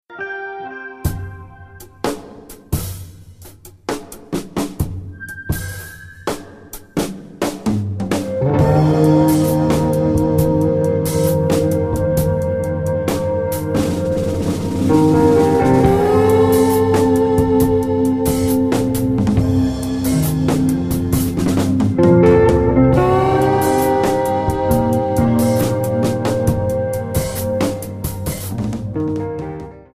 Alternative,Blues